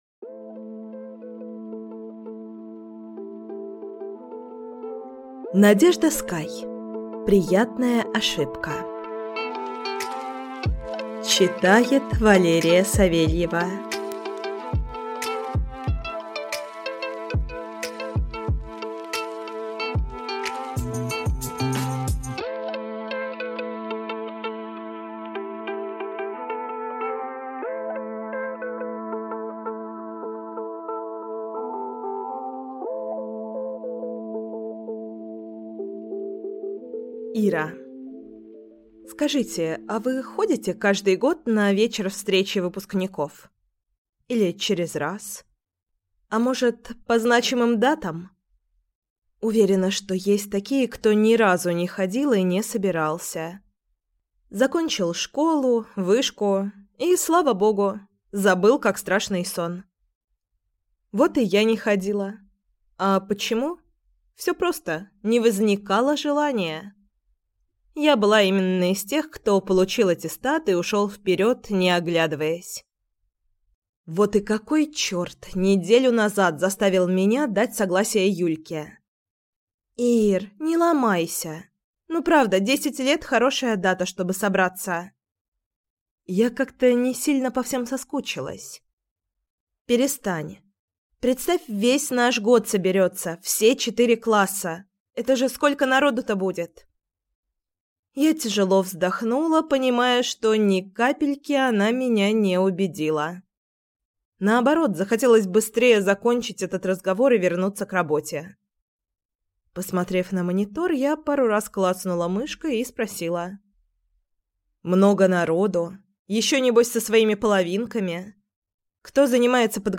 Аудиокнига Приятная ошибка | Библиотека аудиокниг